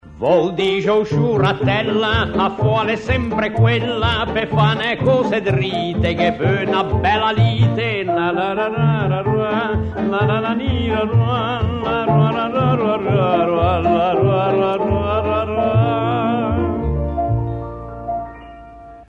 a sîgla da trasmisción